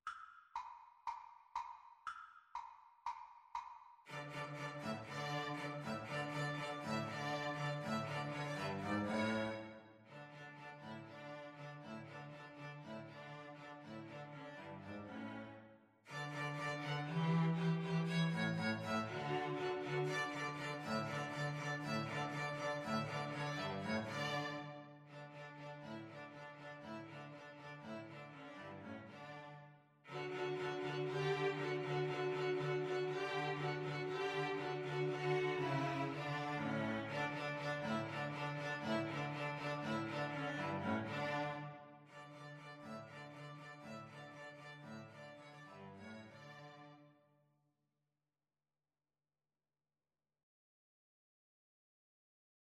Allegro (View more music marked Allegro)
String trio  (View more Easy String trio Music)